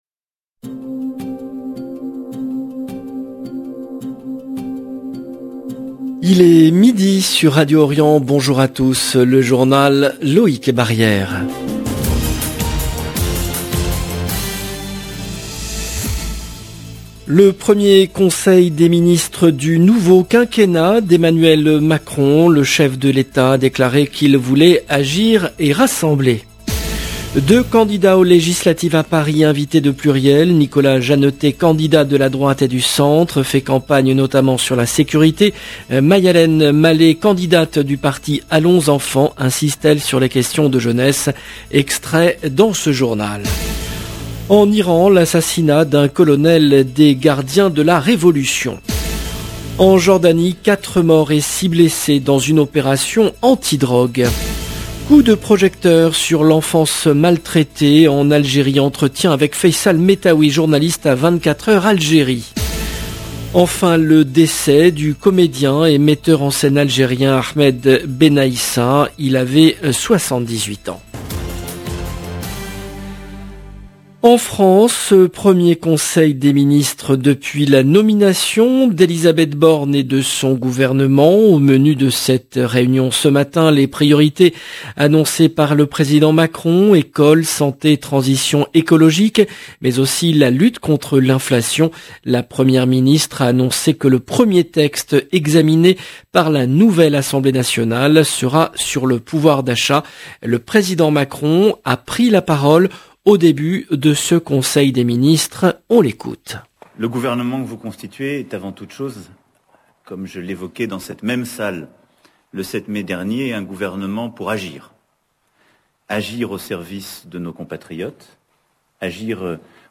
LB JOURNAL EN LANGUE FRANÇAISE